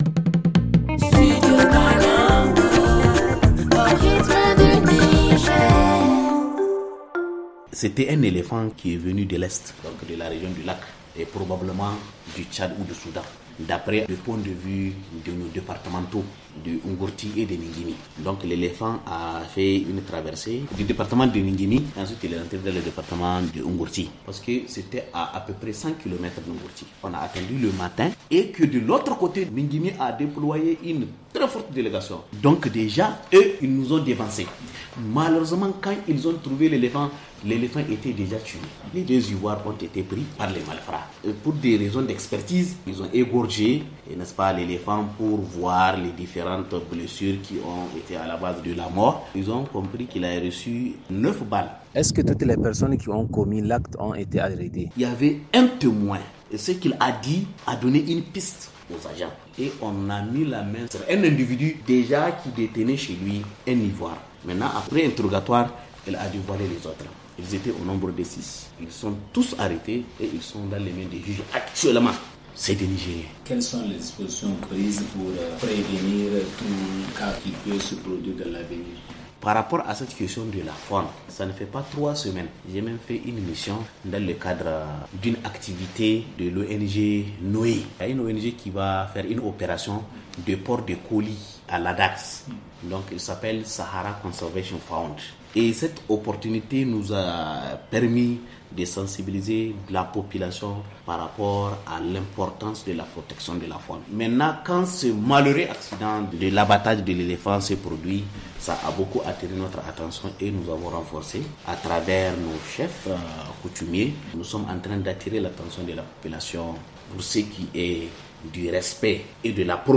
Maman Saguirou, préfet de N’gourti rappelle la position du Niger et de ses partenaires dans la protection de la faune.